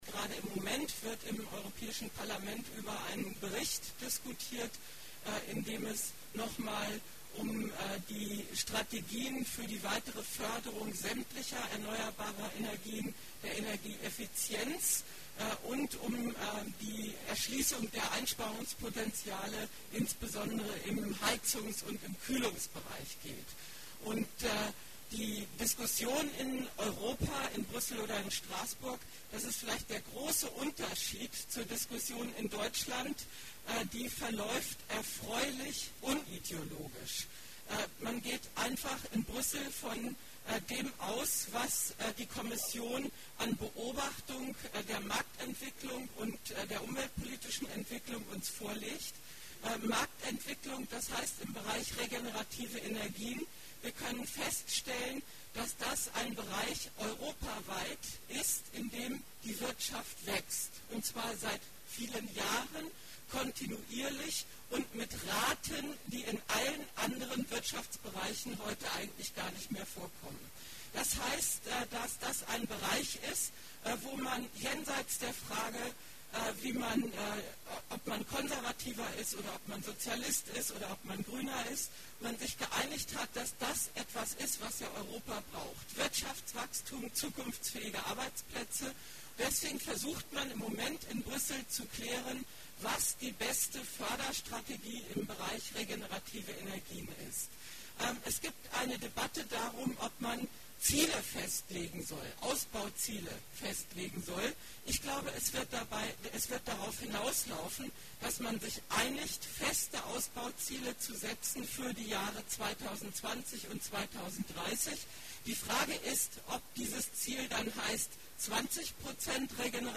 Solarwärme-Infotour in Dannenberg
Hier gibt es O-Ton zum herunterladen: